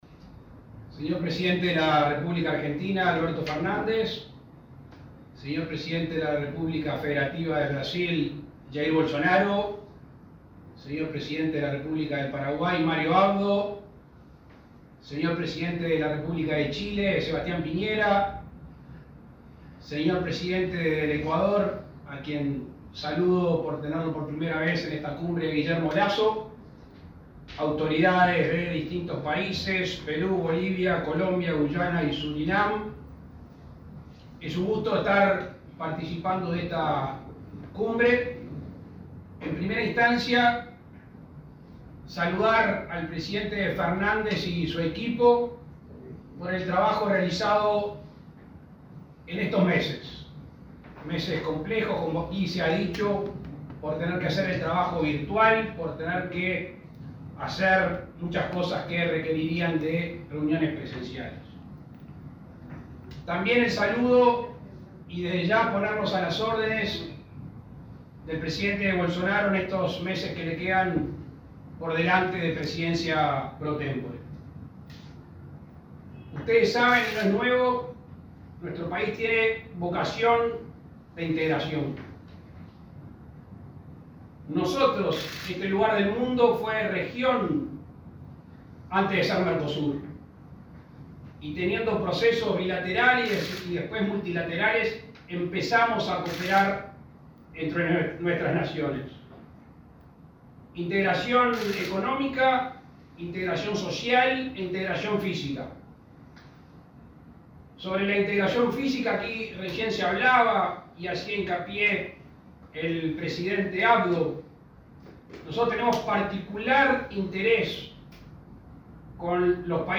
Palabras del presidente Luis Lacalle Pou en LVIII Cumbre de Jefes de Estado del Mercosur
El presidente de la República, Luis Lacalle Pou, participó de la LVIII Cumbre de Jefes de Estado del Mercosur, realizada de modo virtual, este 8 de